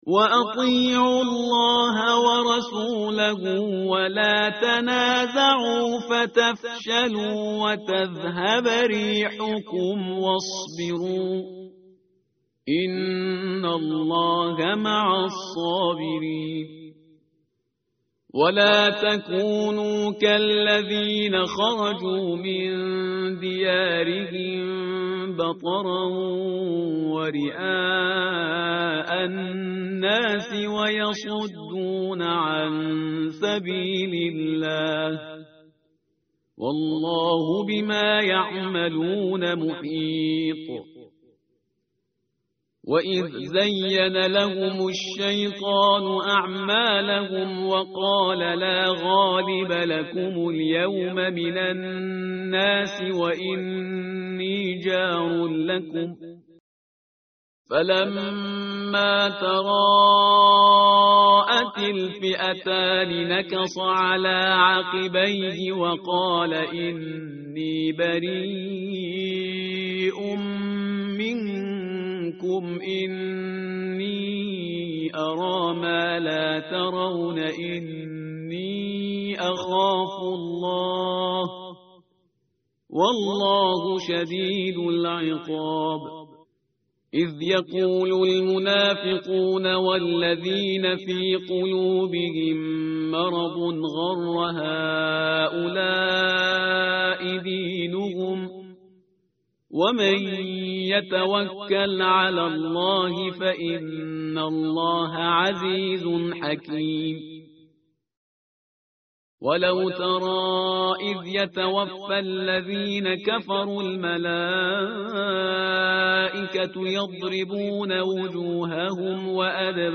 tartil_parhizgar_page_183.mp3